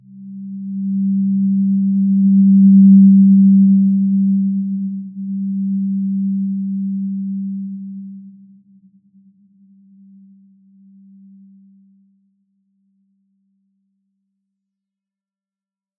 Simple-Glow-G3-f.wav